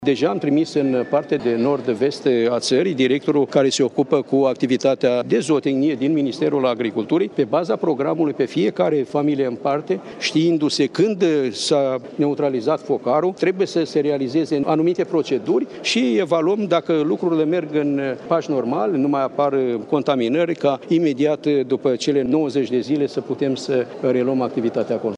Ministrul Agriculturii a făcut această declaraţie la finalul dezbaterilor din Camera Deputaţilor la moţiunea simplă în care liberalii critică modul în care a gestionat criza pestei porcine şi îi cer demisia.